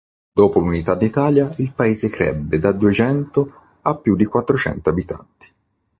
pa‧é‧se
Pronúnciase como (IPA)
/paˈe.ze/